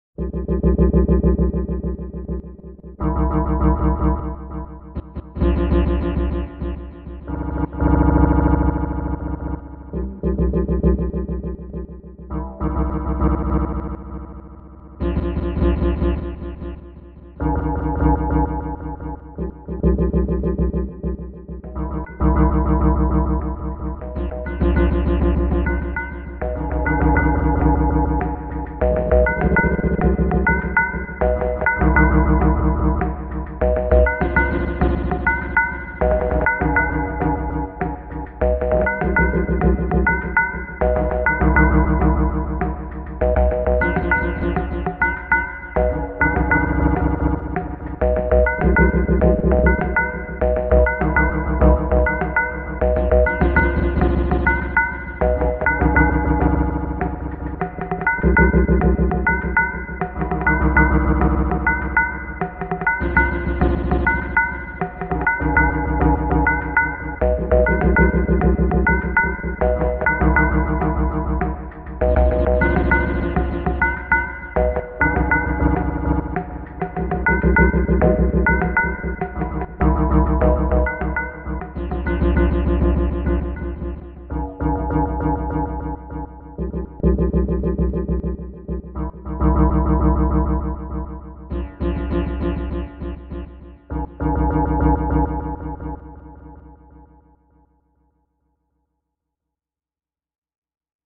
Pieza de Dark Ambient
melodía
repetitivo
sintetizador
Sonidos: Música